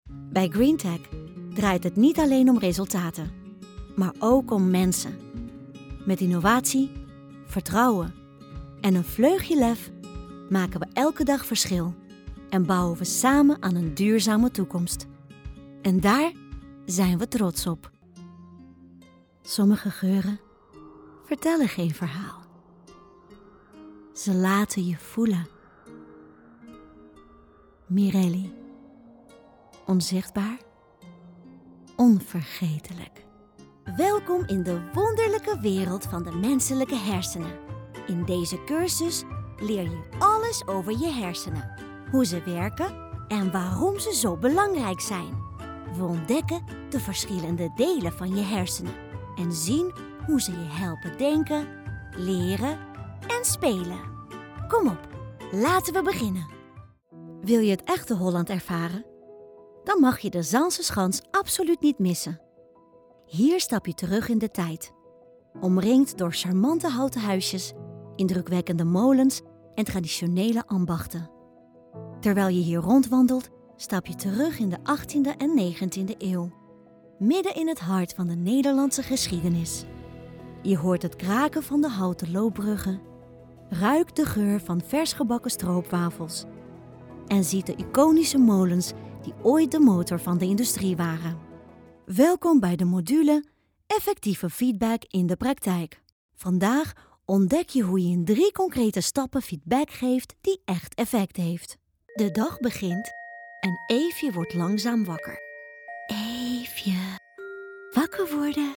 Esquentar
Amigáveis
Caprichoso